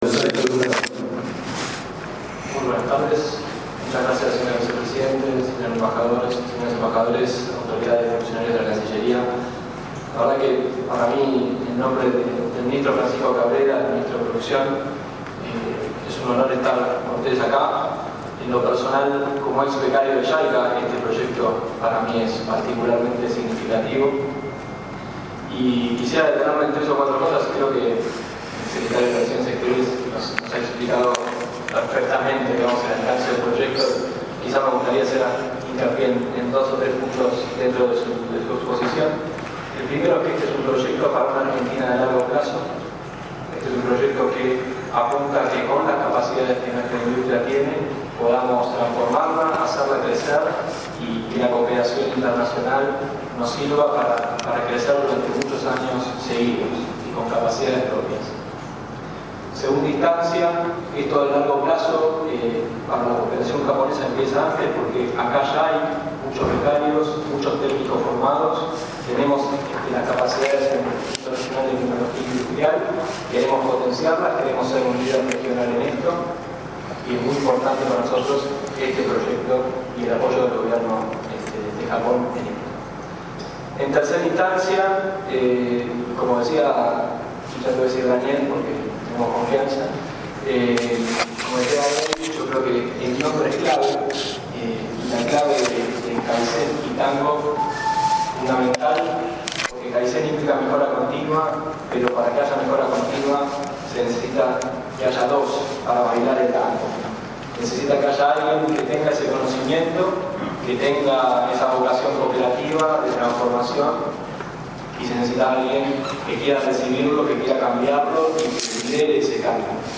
En las bellísimas  instalaciones del Palacio San Martín conservadas y cuidadas como en sus orígenes  el miércoles 25  de octubre se lanzó oficialmente el proyecto “Red de Asistencia Técnica para Oportunidades Globales de Kaizen”, denominado  “Kaizen-Tango”
Audio: Subsecretario de Gestión Productiva del Ministerio de Producción, Sergio Drucaroff.